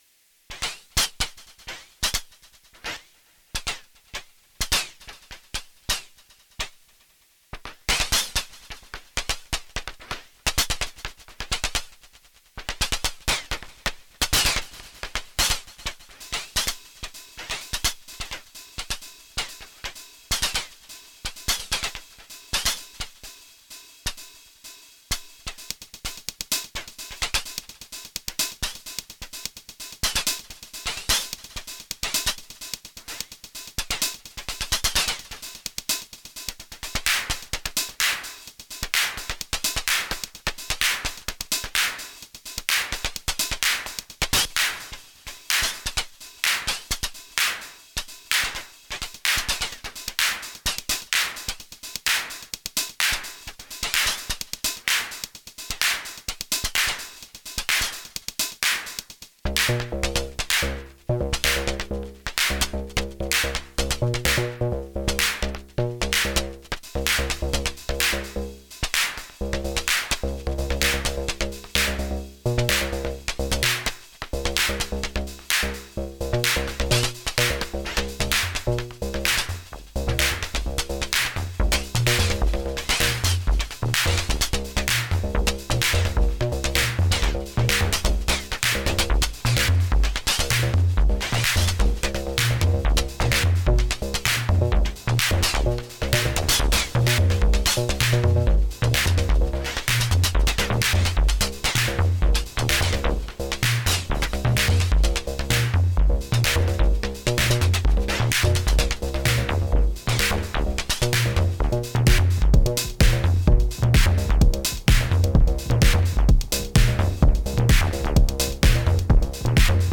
Longer Techno System/Swarm/AH jam